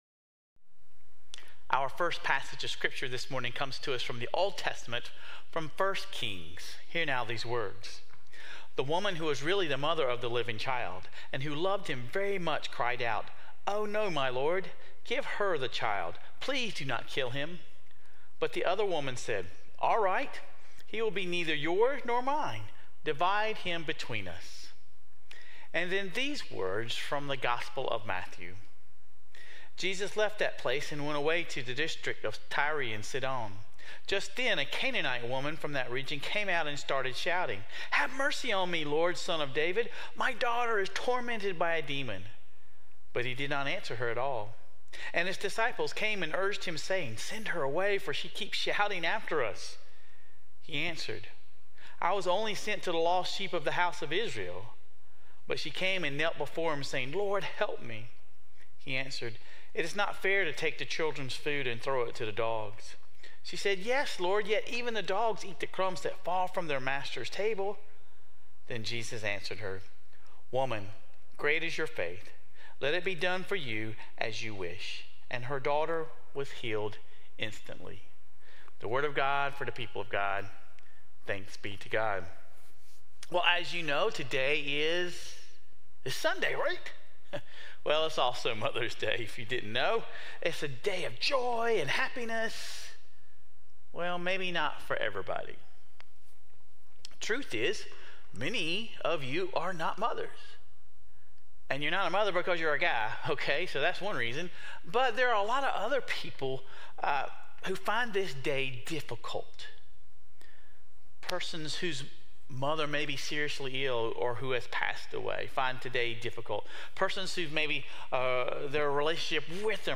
The mothers and women in our lives leave impressions that reflect the One who made us. The highest calling for any person — man or woman — is simply to reflect the marks, the characteristics of the One who created us. Sermon Reflections: How does the Canaanite woman in the passage from Matthew demonstrate perseverance?